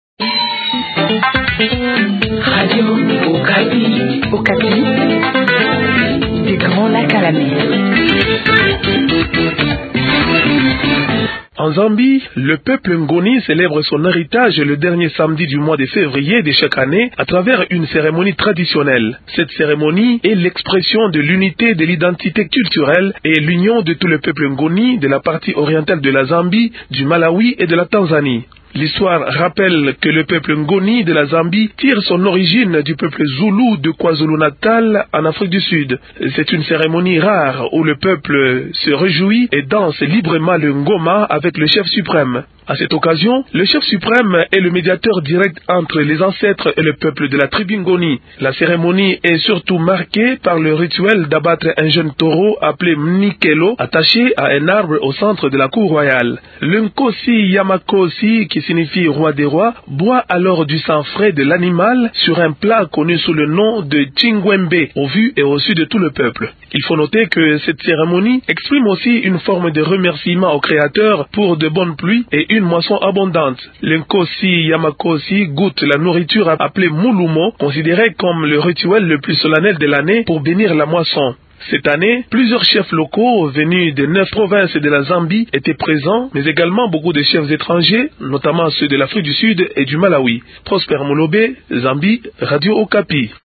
Depuis Lusaka en Zambie